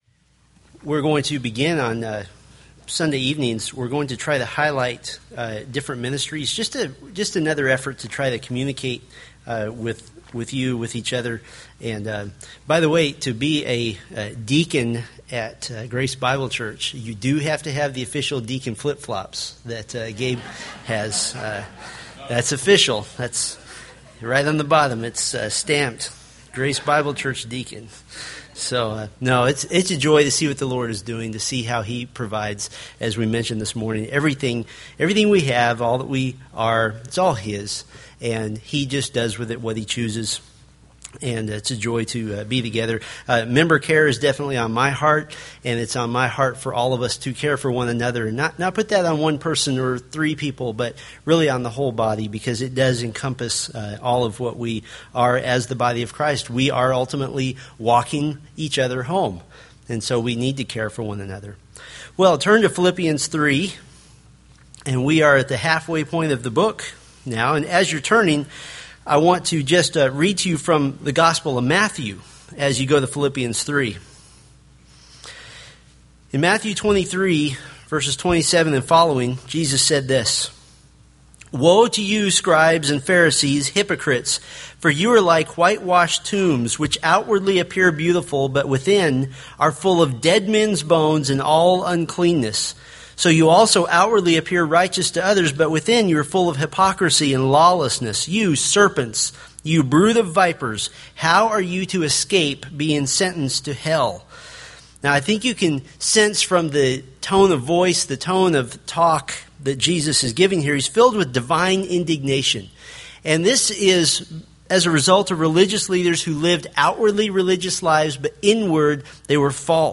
Philippians Sermon Series: Philippians Download